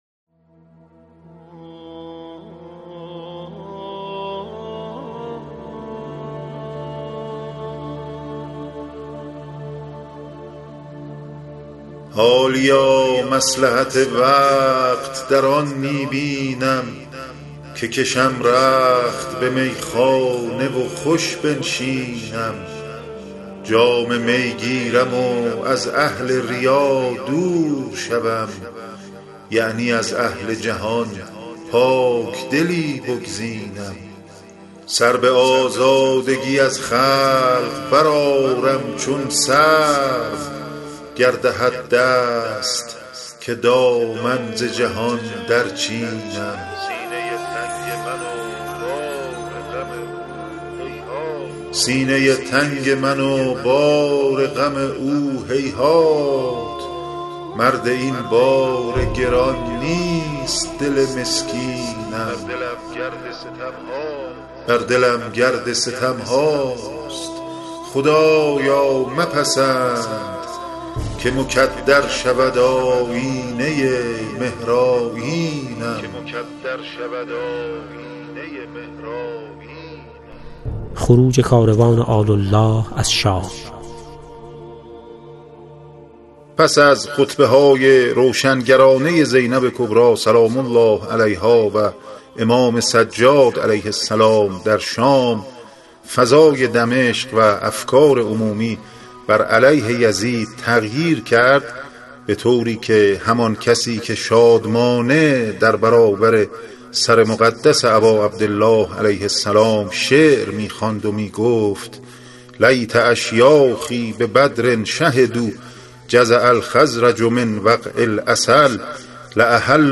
روایت منزل به منزل کاروان اسرای کربلا (به صورت صباحا و مساء) به همت گروه التجا، مصائب کاروان اسرا، بر اساس منابع معتبر تاریخی در چهل قسمت به شکل فایل صوتی، آماده و به دوستداران امام عصر علیه‌السلام تقدیم شده است.